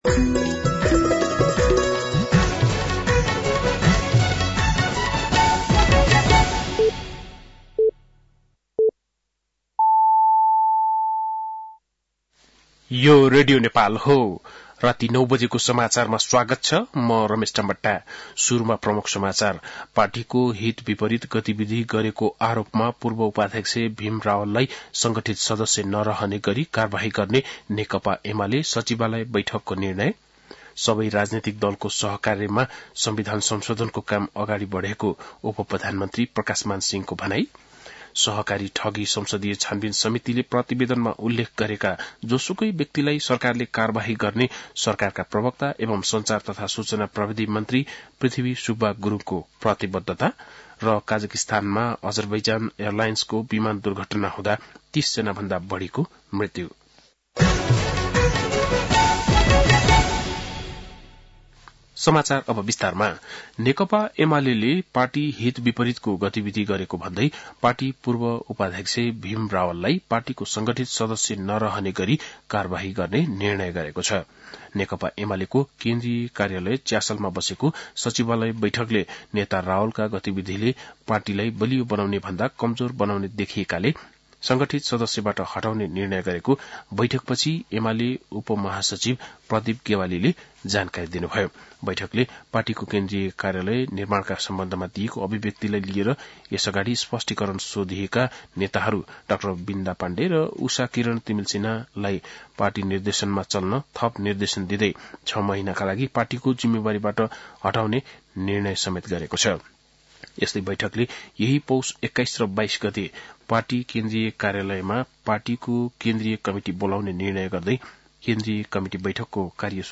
बेलुकी ९ बजेको नेपाली समाचार : ११ पुष , २०८१
9-PM-Nepali-News-9-10.mp3